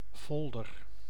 Ääntäminen
IPA: /fɔl.dœɾ/